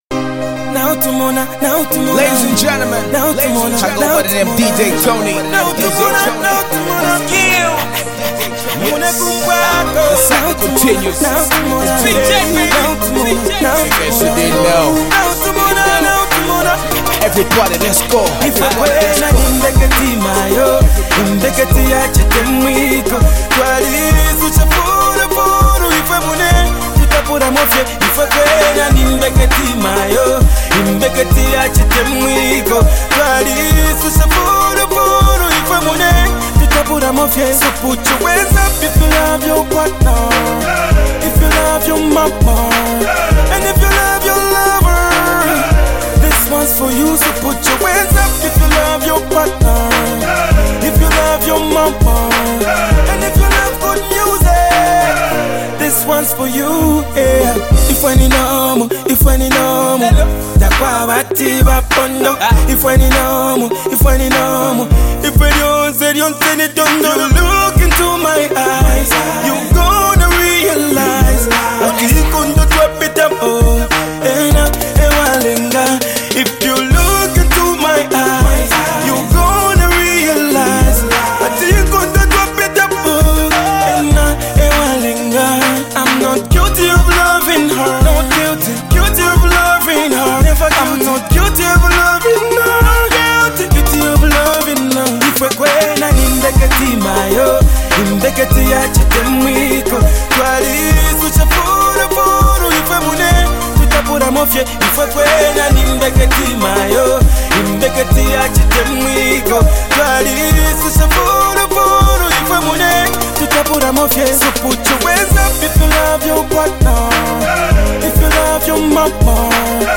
This energetic track resonated with fans